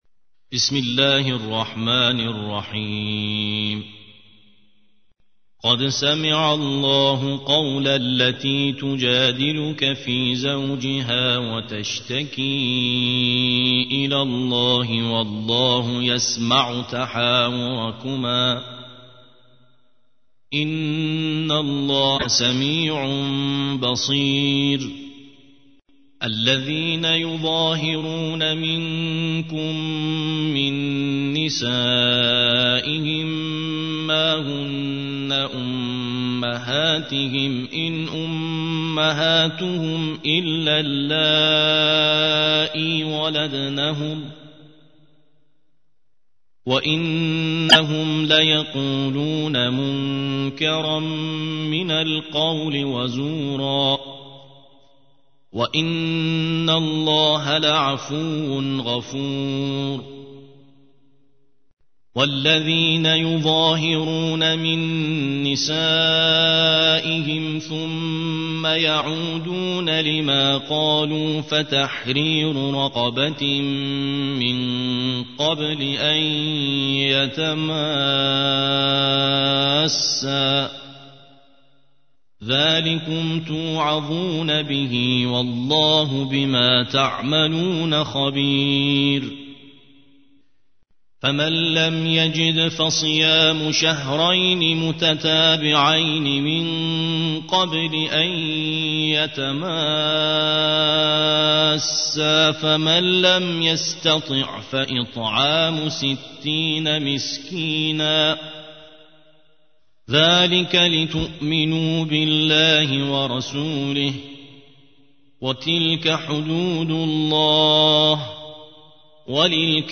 ترتیل سوره مجادله